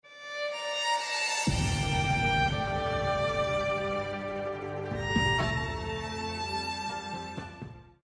伤感-10秒.mp3